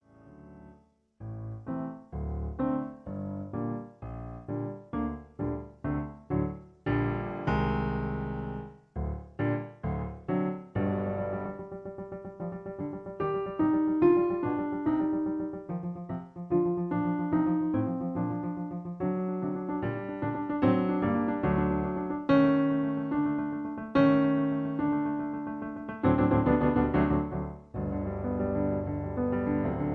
In G. Piano Accompaniment